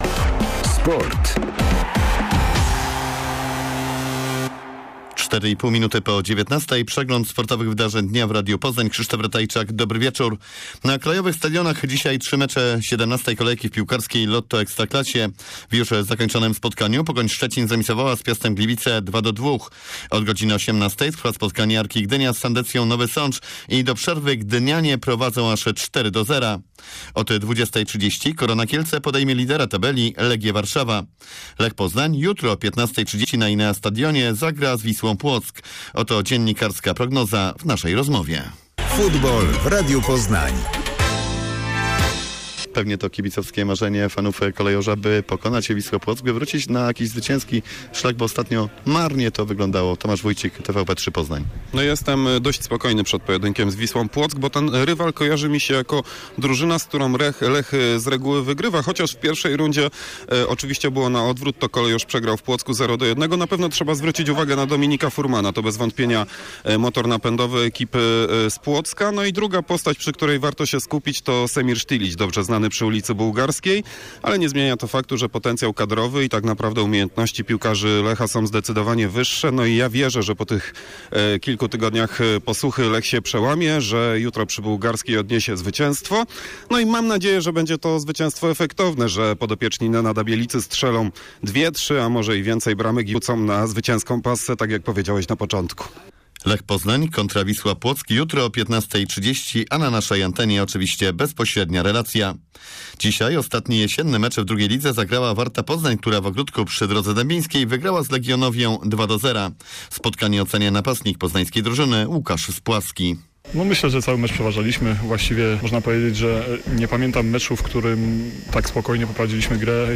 25.11 serwis sportowy godz.19:05